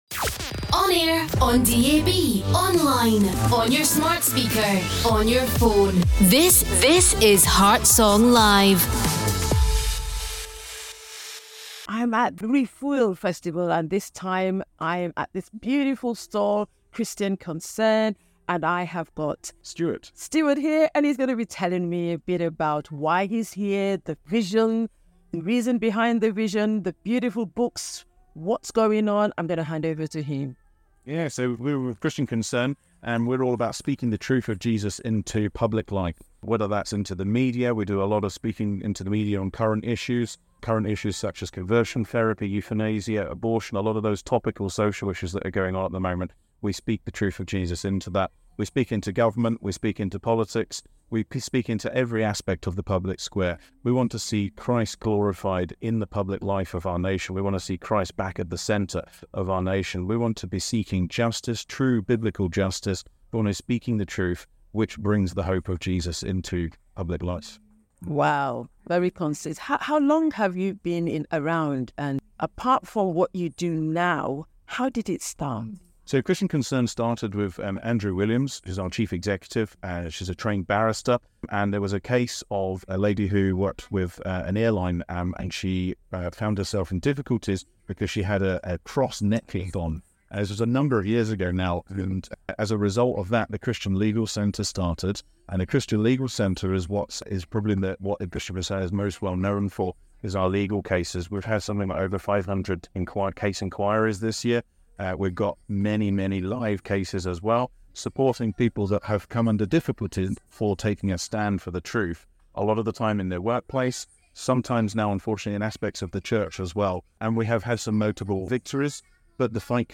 Q&A: On the Ground with Christian Concern at Refuel FestivalQ